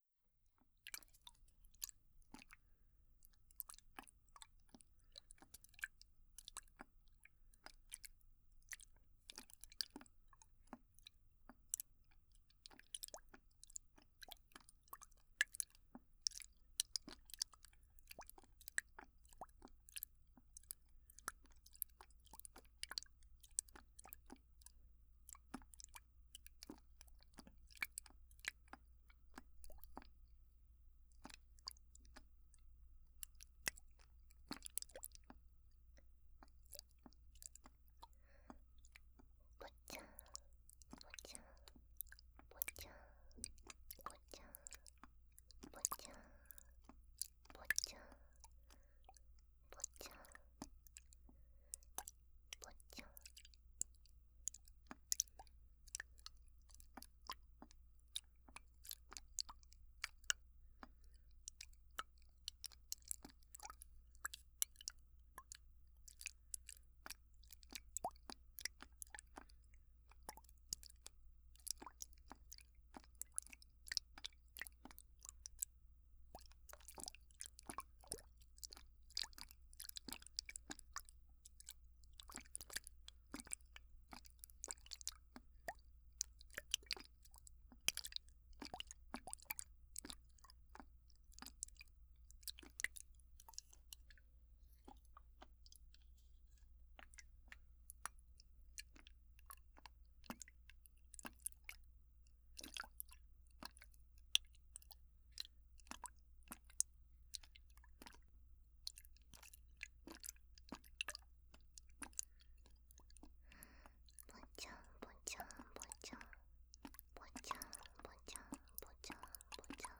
02.水音系パートのみ.wav